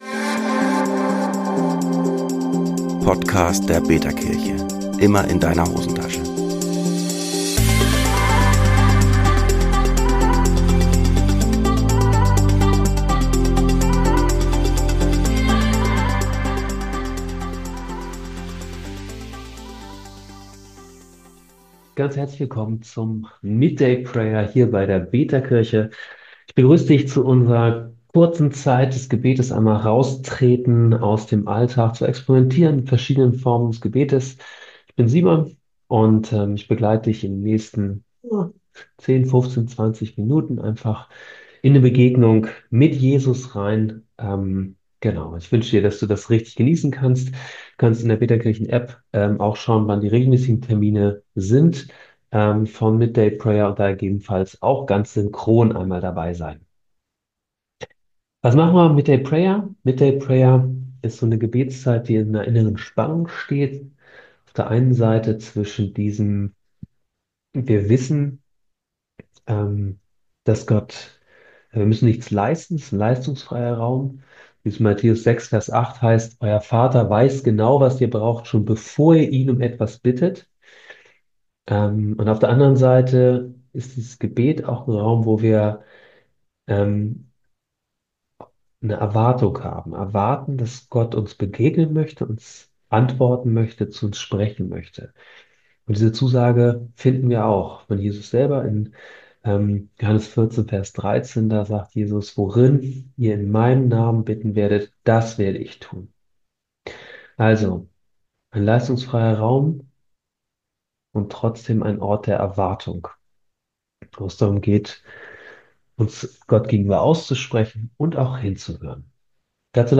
Willkommen zum Gebet, eine gute Begegnung mit Jesus wünschen wir Dir!